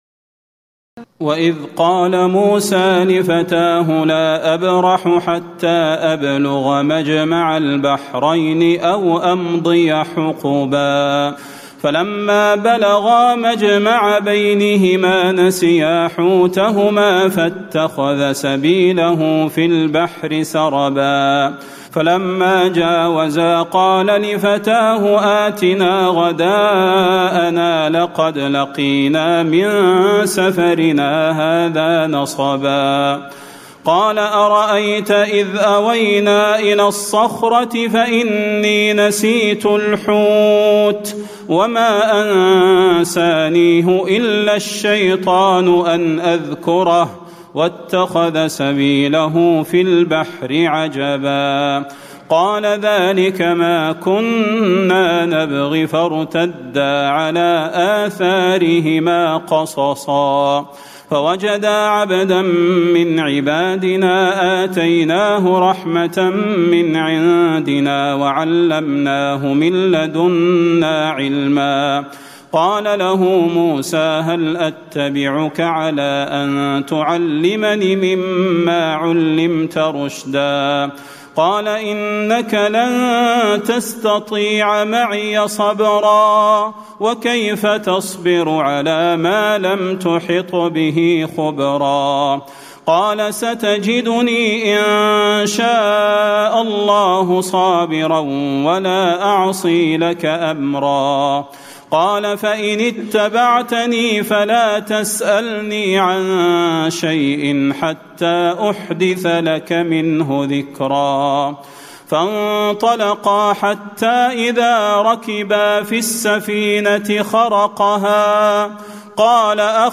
تراويح الليلة الخامسة عشر رمضان 1436هـ من سورتي الكهف (60-110) و مريم كاملة Taraweeh 15 st night Ramadan 1436H from Surah Al-Kahf and Maryam > تراويح الحرم النبوي عام 1436 🕌 > التراويح - تلاوات الحرمين